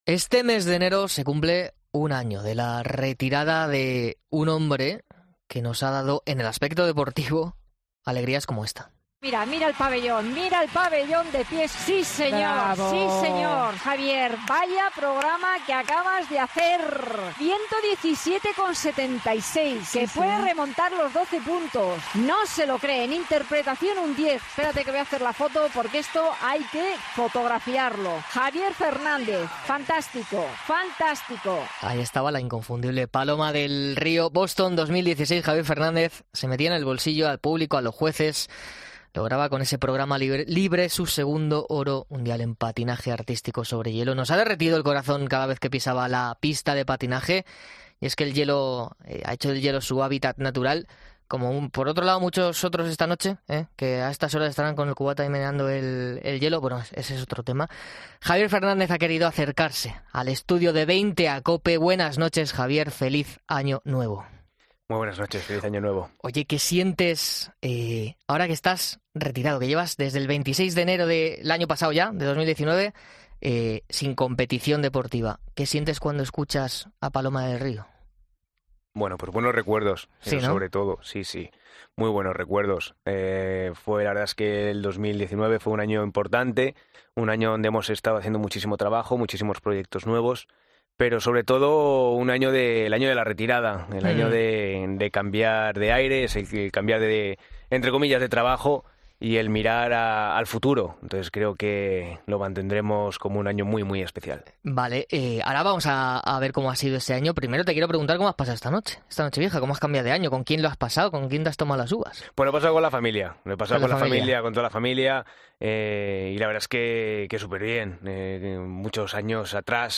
Tras casi 365 días lejos de la competición, ha visitado el estudio de Veinte a COPE para celebrar el Año Nuevo y hacer balance de su presente, pasado y futuro .